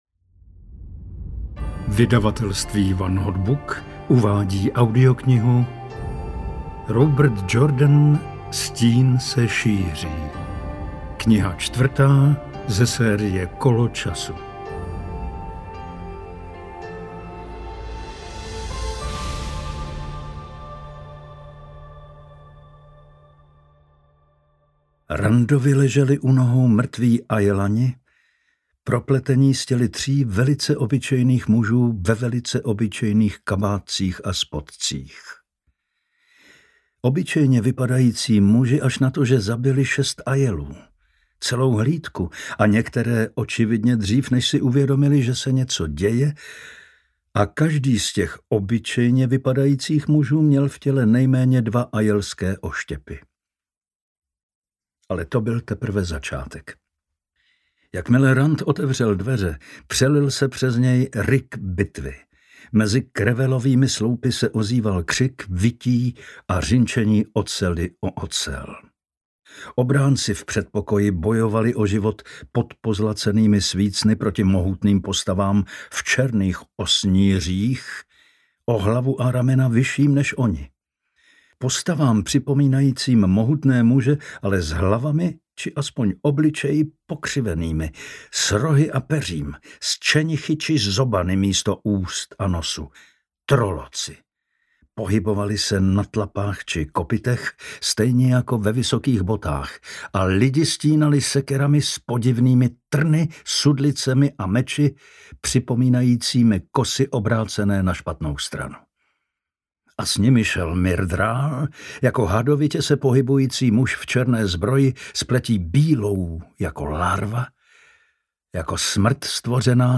Stín se šíří audiokniha
Ukázka z knihy